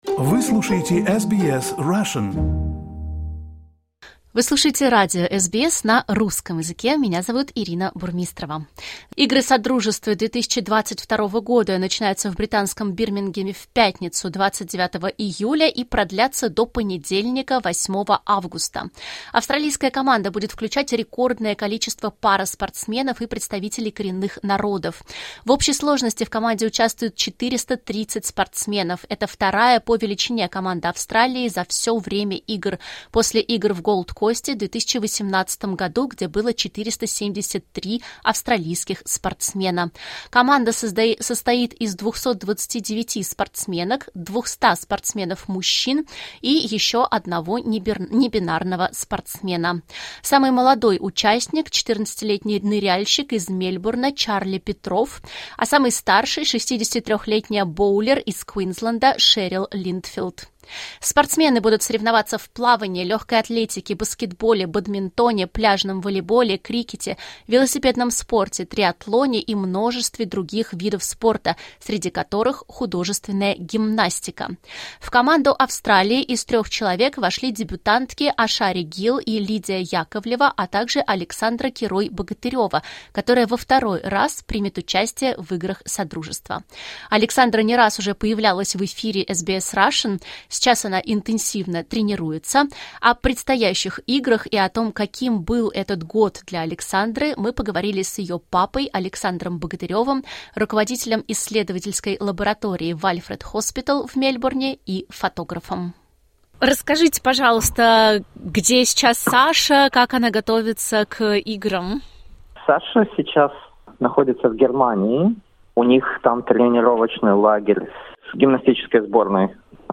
В этом интервью